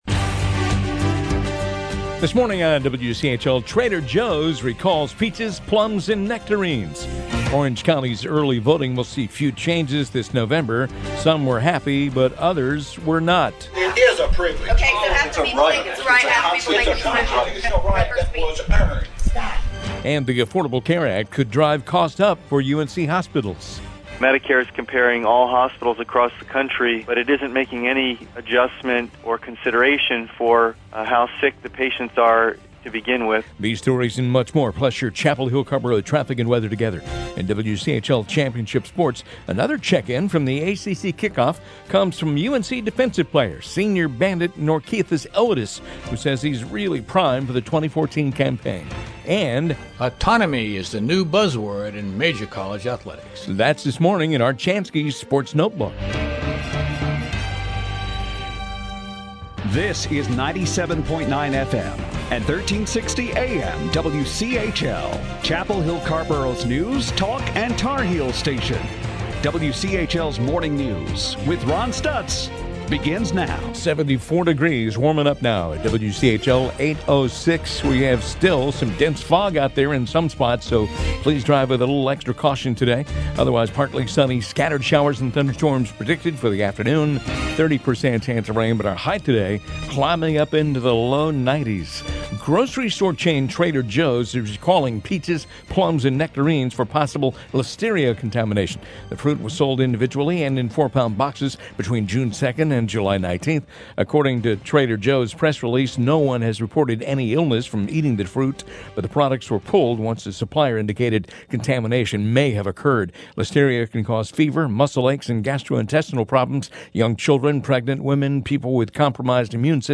WCHL MORNING NEWS HOUR 3.mp3